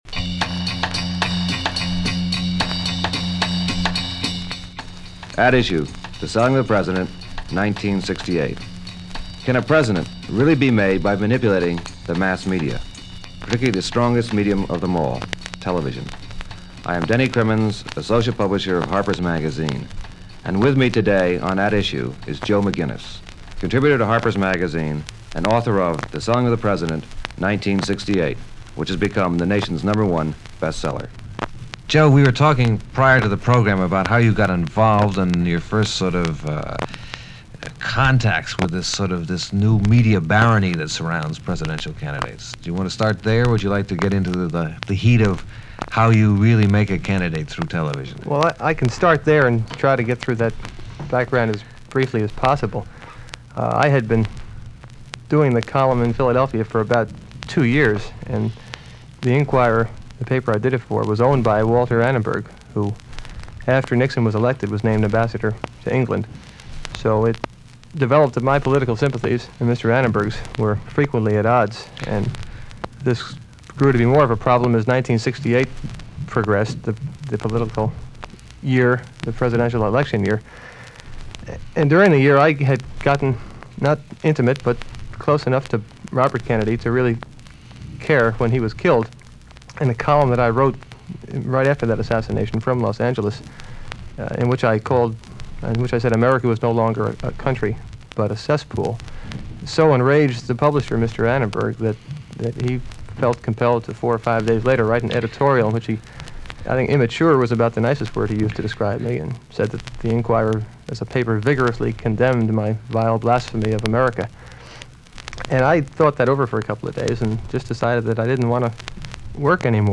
Selling Of The President 1968 – Interview with Joe McGinniss